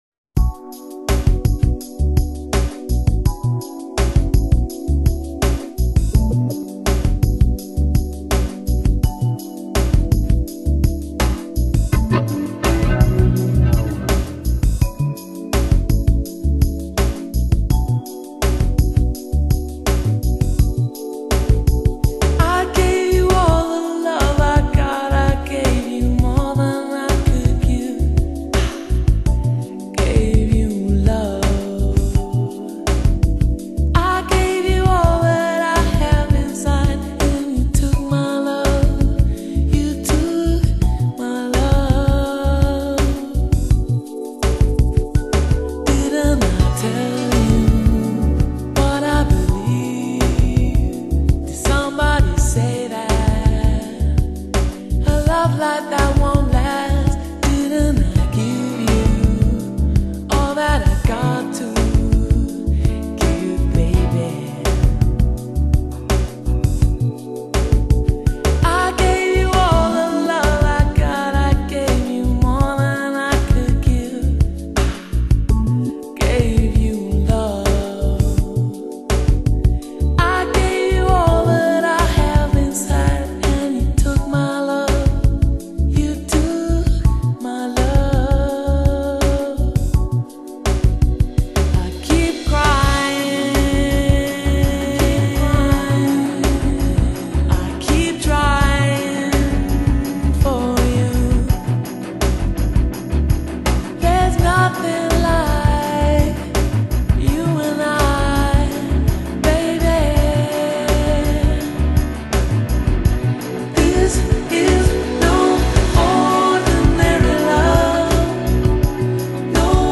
Genre: lounge, easy listening
休闲、怀旧、风格多样，值得一听的一张新碟。